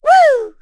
Laudia-Vox_Happy4.wav